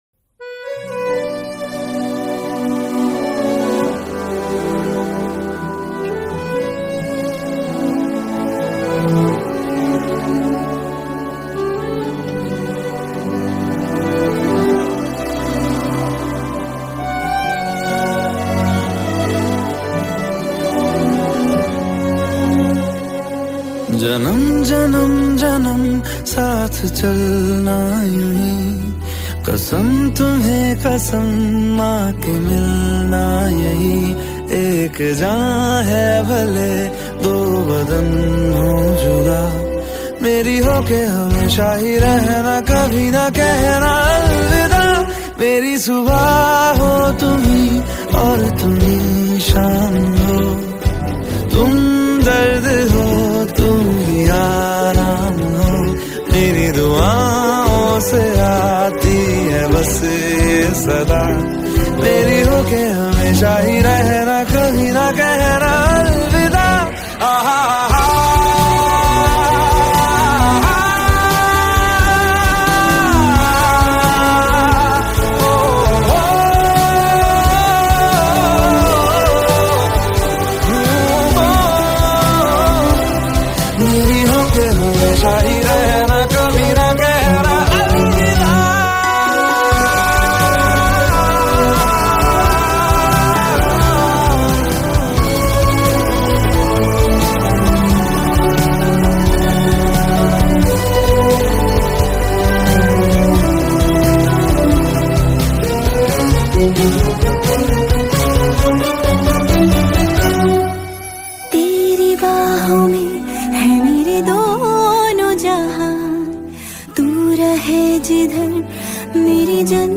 Hindi Song
Skor Angklung